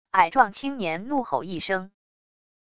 矮壮青年怒吼一声wav音频